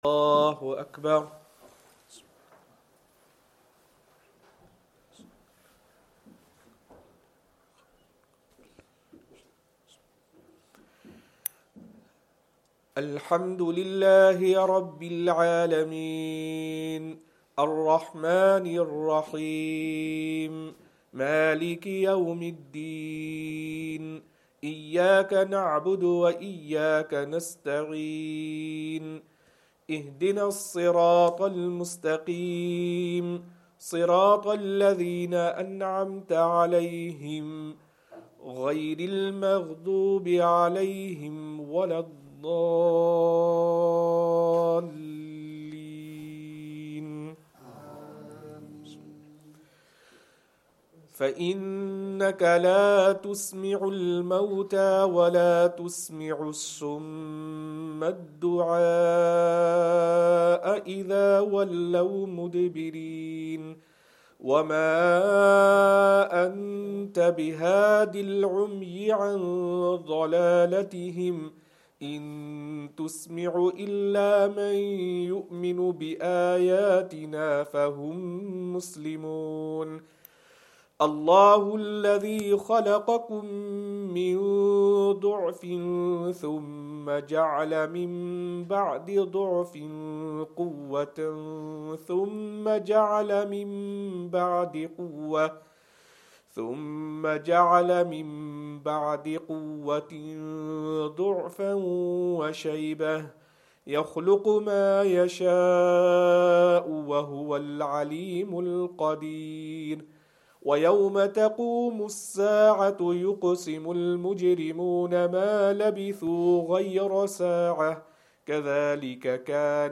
Isha
Madni Masjid, Langside Road, Glasgow